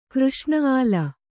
♪ křṣṇa āla